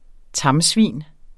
Udtale [ ˈtɑm- ]